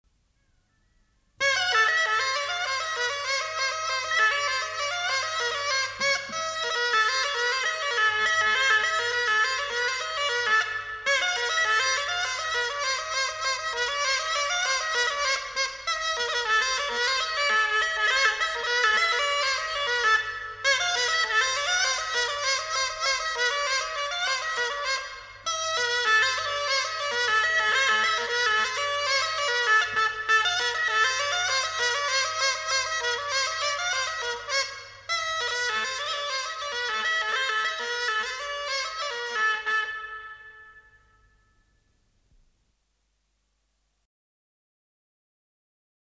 Голоса уходящего века (Курское село Илёк) Чебатуха (рожок, инструментальный наигрыш)